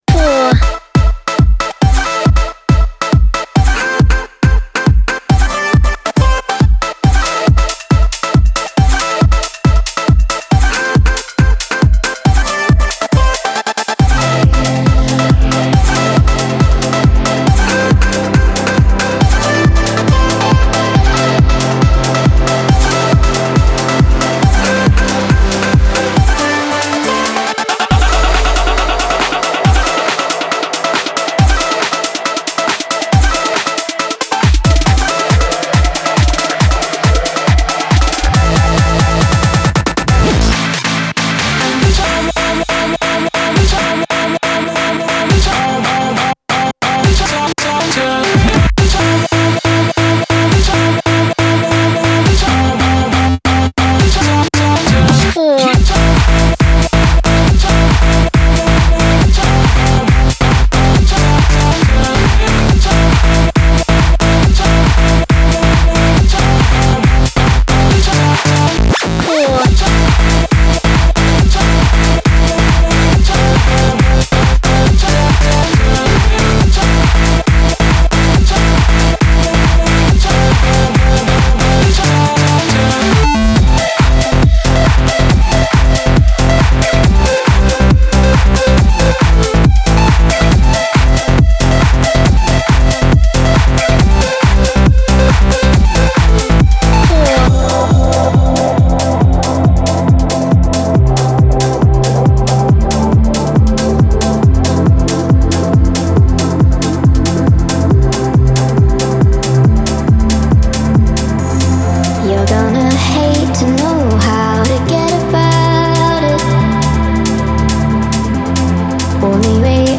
BPM138-138
Audio QualityPerfect (High Quality)
Full Length Song (not arcade length cut)